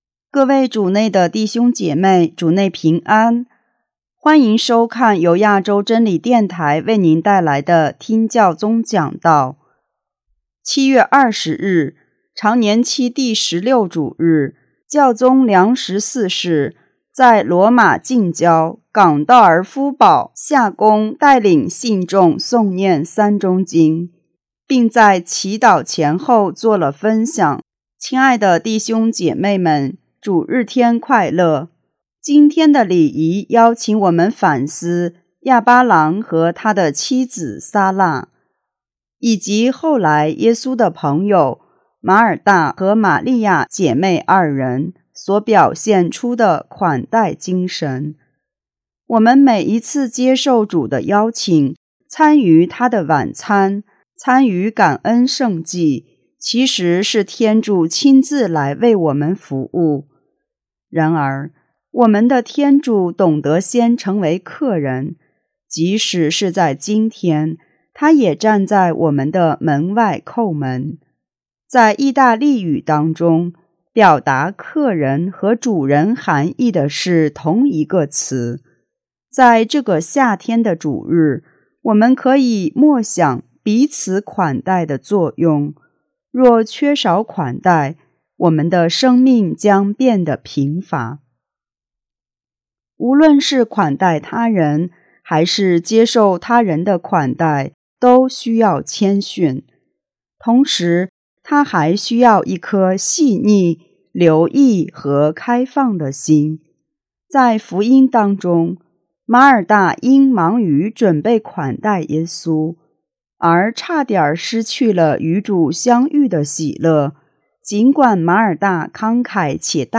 7月20日,常年期第16主日，教宗良十四世在罗马近郊冈道尔夫堡夏宫带领信众诵念“三钟经”，并做祈祷前后做了分享。